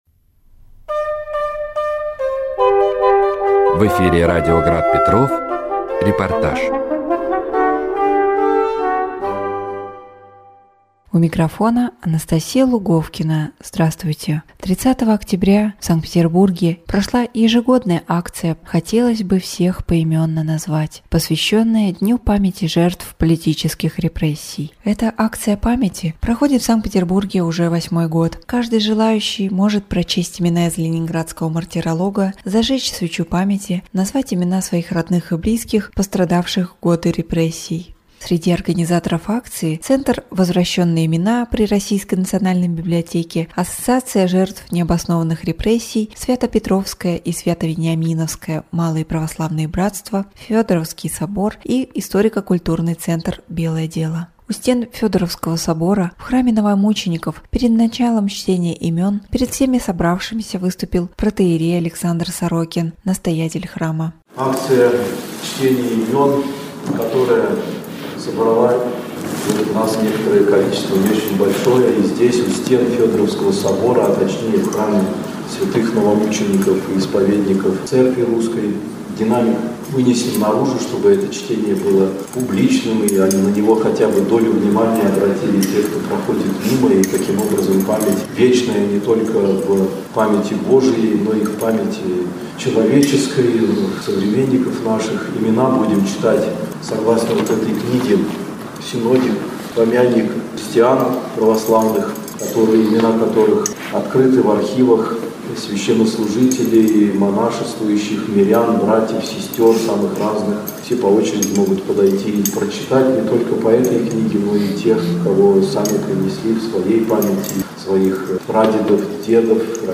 3. Репортаж
Восьмой раз в Санкт-Петербурге в День памяти жертв политических репрессий состоялась акция «Хотелось бы всех поименно назвать…».